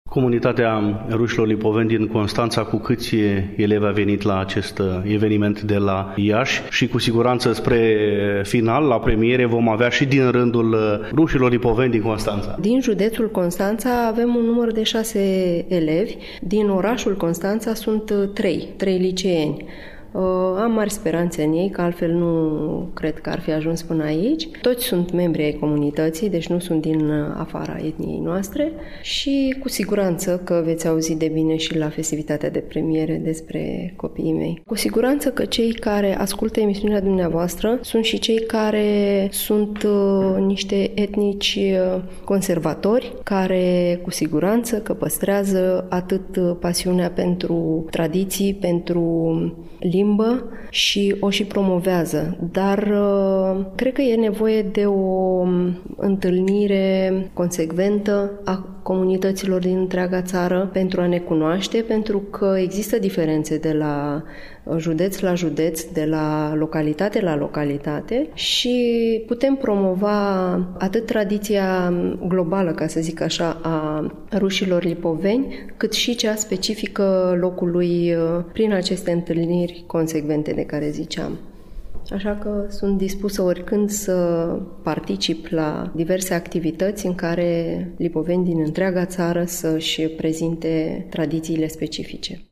Al doilea interlocutor al emisunii este doamna profesoară de limba rusă